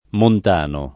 mont#no] agg. — sim. il pers. m. stor., top. e cogn. Montano, il top. e cogn. Montana, i cogn. Montan [mont#n] e Montani — tra i top., i comuni di Montano-Lucino [mont#no lu©&no] (Lomb.) e Montano Antilia [mont#no ant&lLa] (Camp.)